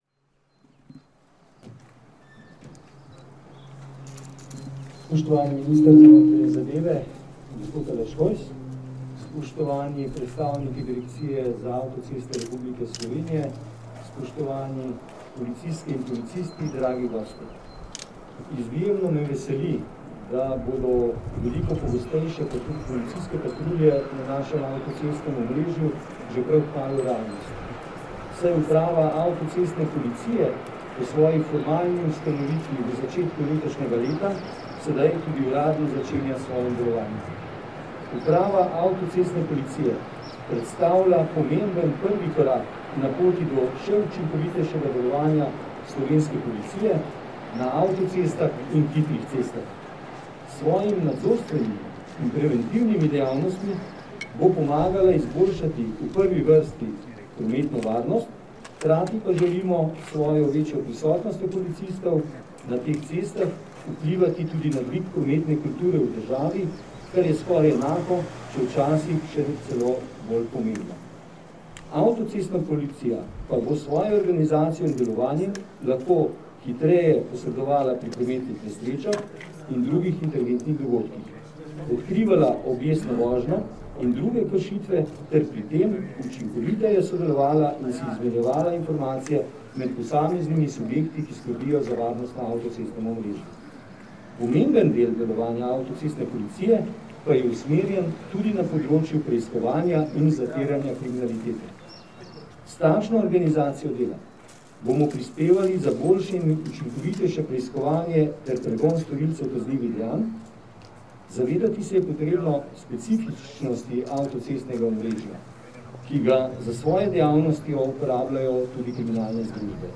Dogodka ob otvoritvi UAP so se v Postojni, kjer je sedež novoustanovljene uprave, danes, 31. marca 2021, iz MNZ in Policije udeležili minister za notranje zadeve Aleš Hojs, namestnik generalnega direktorja policije Tomaž Pečjak in direktor uprave Andrej Jurič.
Zvočni posnetek govora namestnika generalnega direktorja policije Tomaža Pečjaka